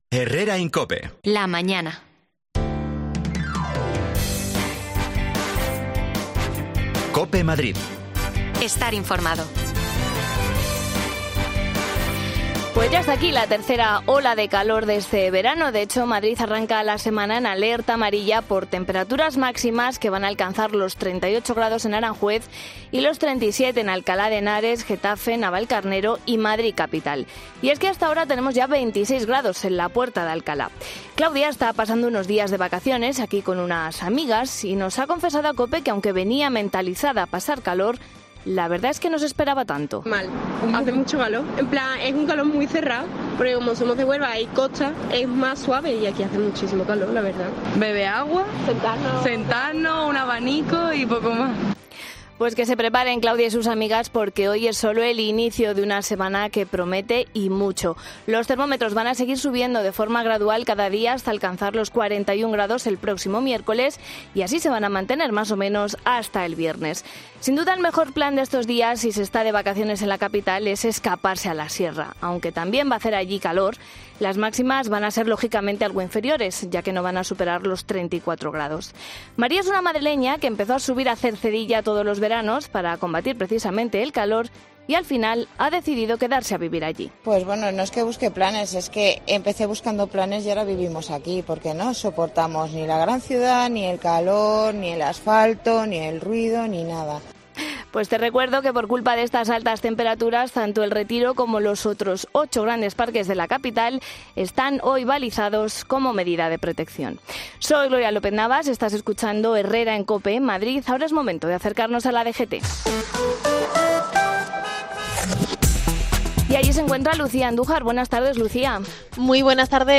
Hablamos con una farmacia que nos explica cómo han subido estas ventas